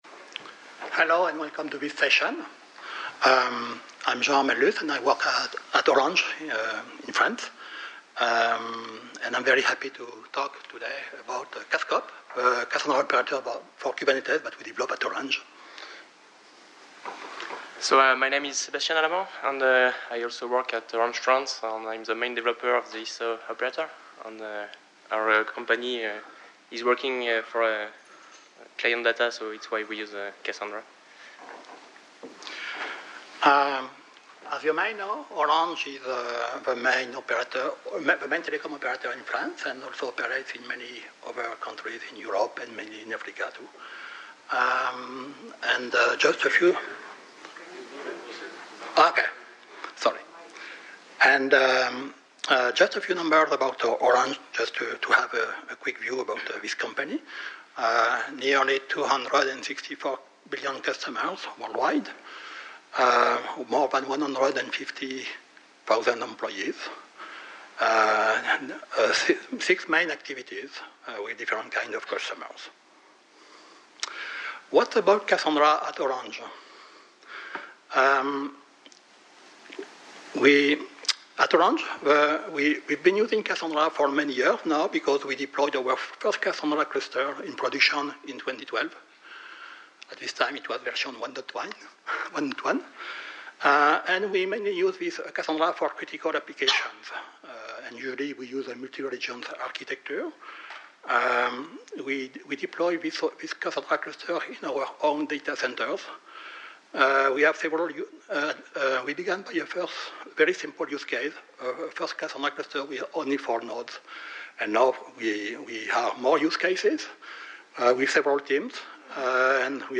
This presentation will describe the initial experience building and using CassKop, an operator developed for running Cassandra on top of Kubernetes. CassKop works as a usual K8S controller (reconcile the real state with a desired state) and automates the Cassandra operations through JMX.